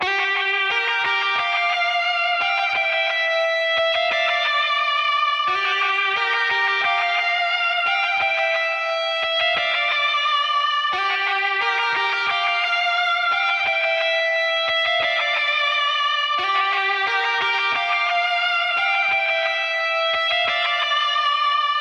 金属混乱1 尖叫的吉他176 Dm
标签： 176 bpm Heavy Metal Loops Guitar Electric Loops 3.67 MB wav Key : D Reason
声道立体声